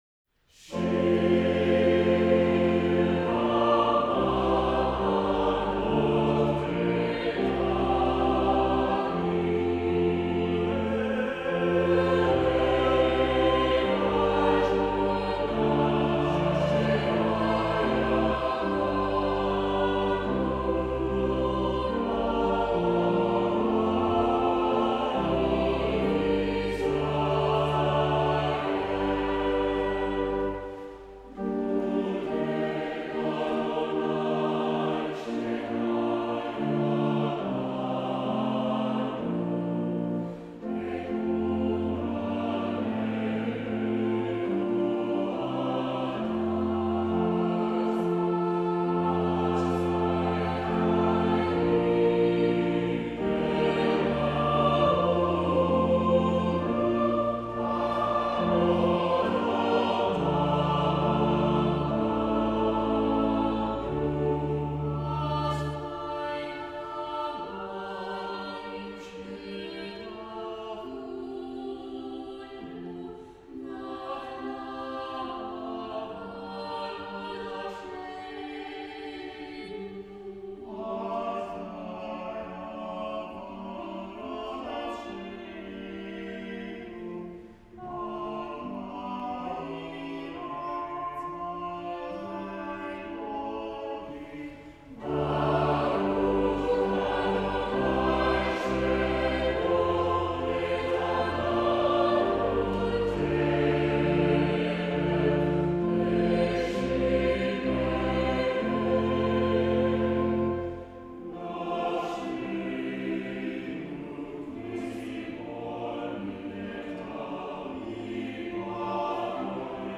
Choral Ensembles
Salamone Rossi: Shir hamma ‘alot l’David, recorded live by the University of Washington Recital Choir, March 2006